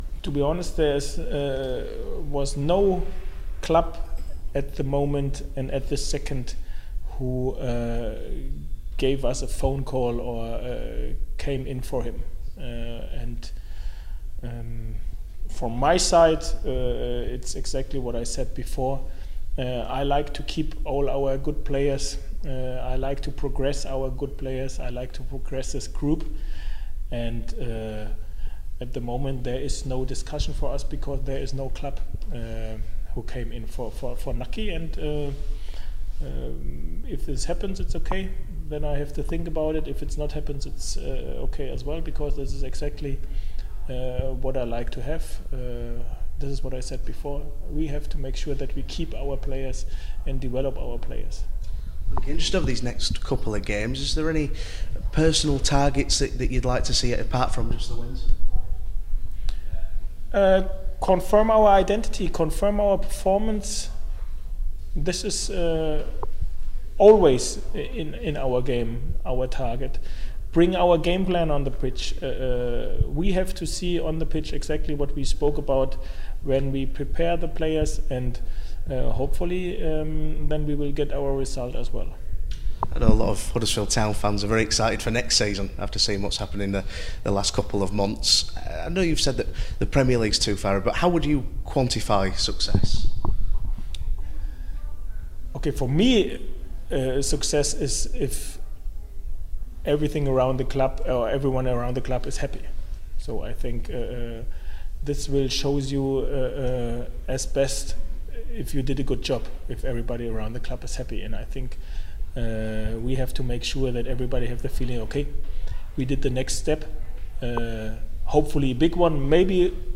Huddersfield Town Head Coach David Wagner talks to Radio Yorkshire ahead of this weekend's game at Bristol City.